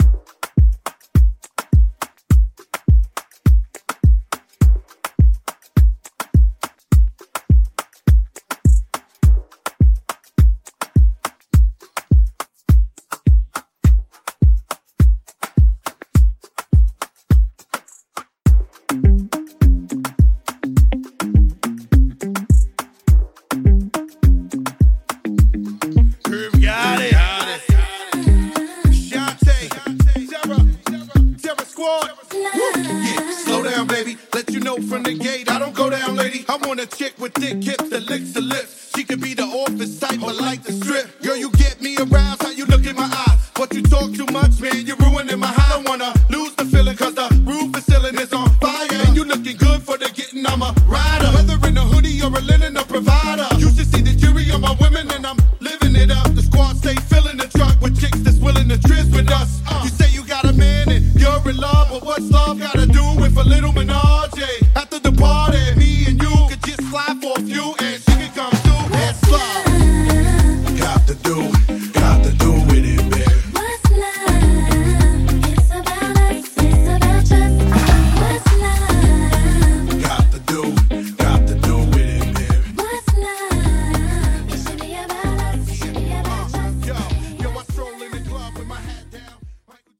Genre: HIPHOP
Dirty BPM: 106 Time